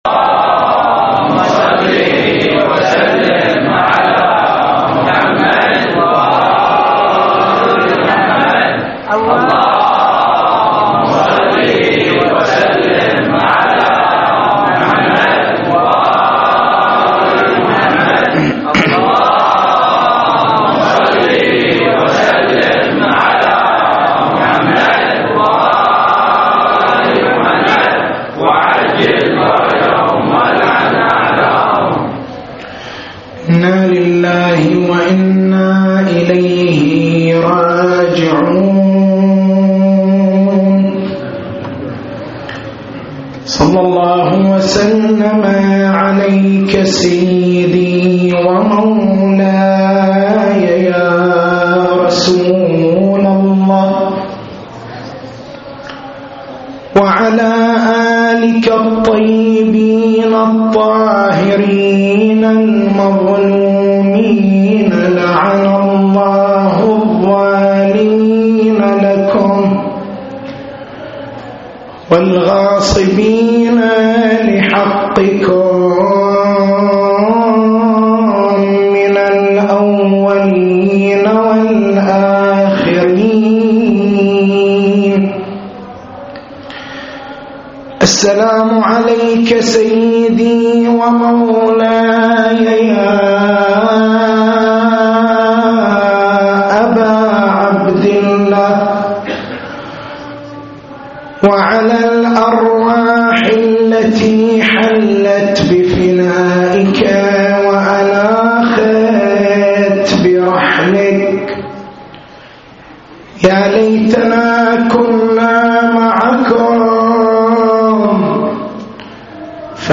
تاريخ المحاضرة
محرم الحرام 1430